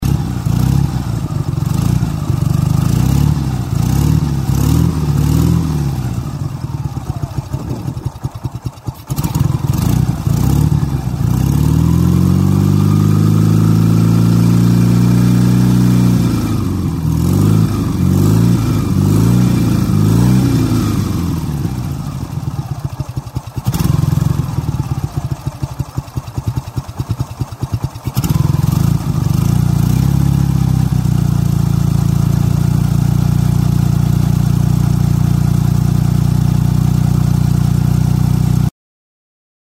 Звуки мотоциклов
Звук советского мотоцикла Урал: